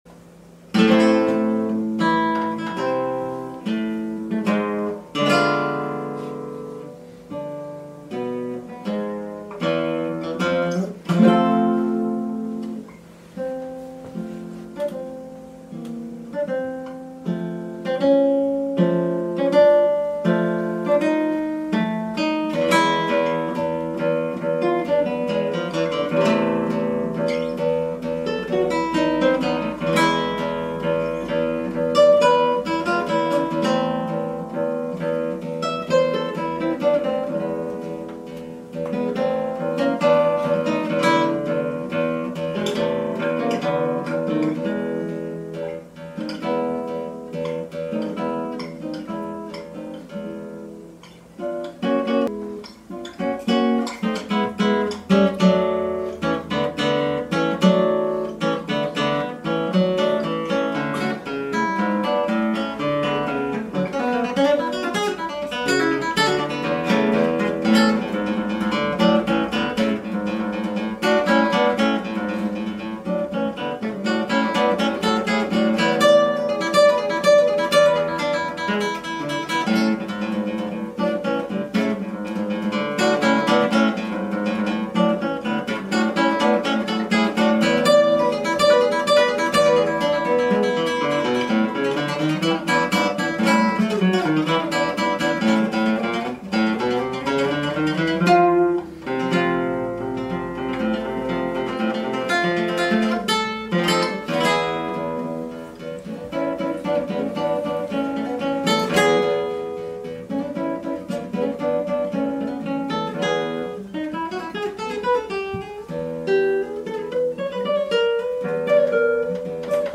Corte Antica di Palazzo Piccolomini
Concerto di chitarra
nella Corte di Palazzo Piccolomini